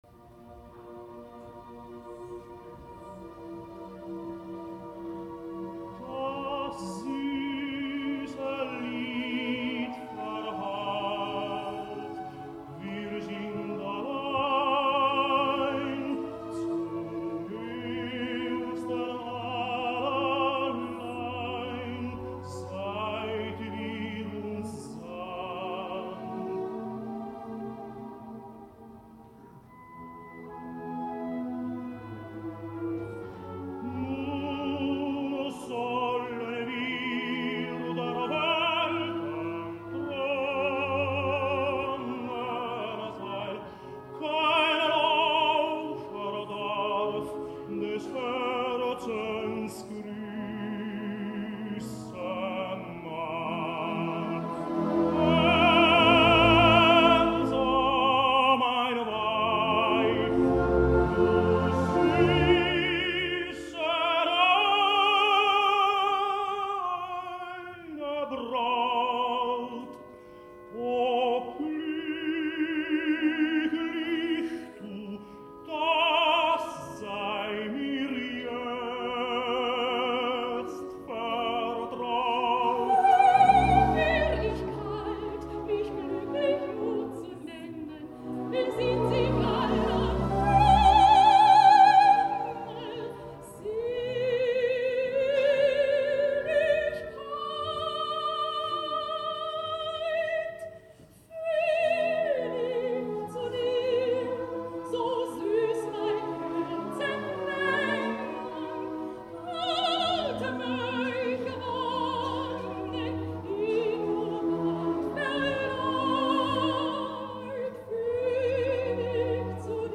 Lohengrin (Richard Wagner) Opera romàntica en tres actes traducció Joaquim Pena Barcelona, Associació Wagneriana 1926
lohengrin-duo-acte-iii.mp3